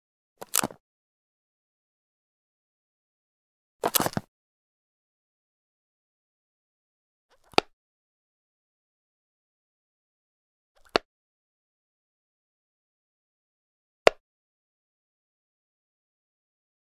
household
Glasses Protective Case Open